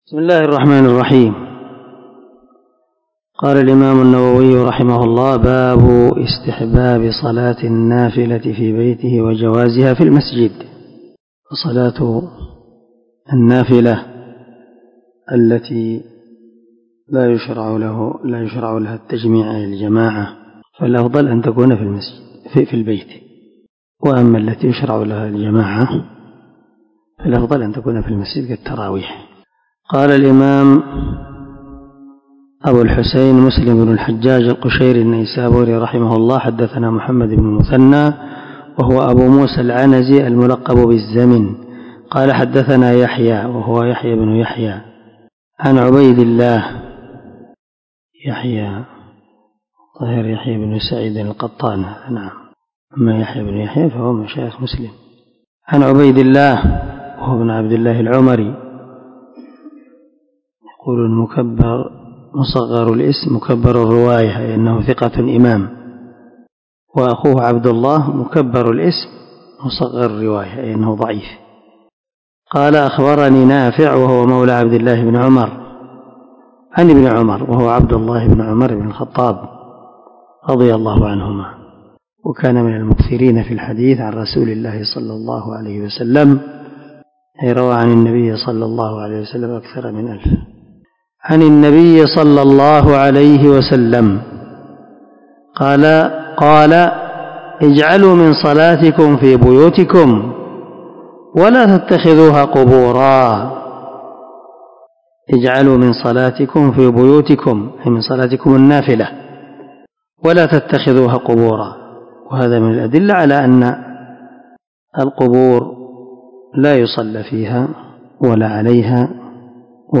472الدرس 40من شرح كتاب صلاة المسافر وقصرها حديث رقم ( 777 – 779 ) من صحيح مسلم